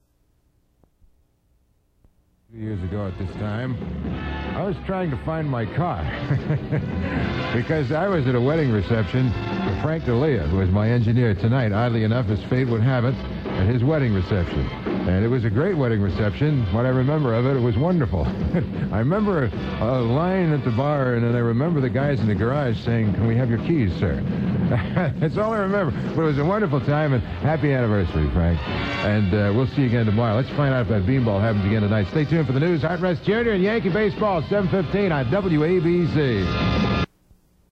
05-05-ingram-close-happy-anniversary.m4a